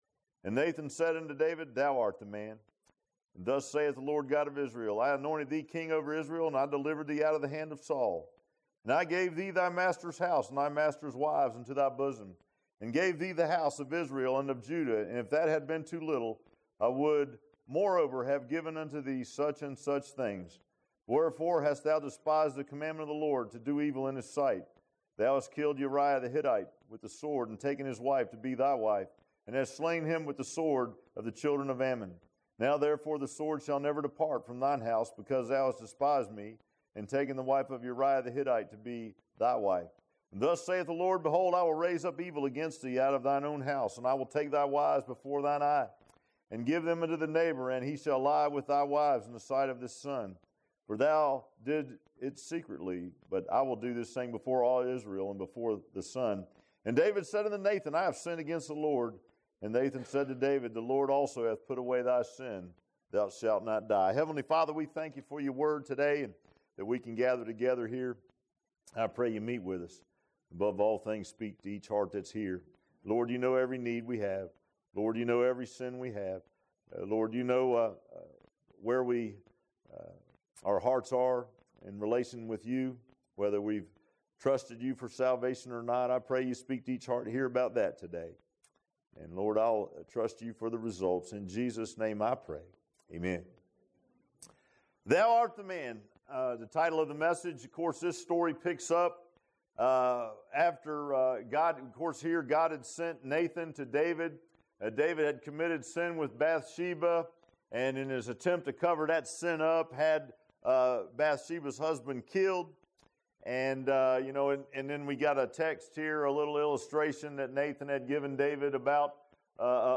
2 Samuel 12:1-13 Service Type: Sunday PM Bible Text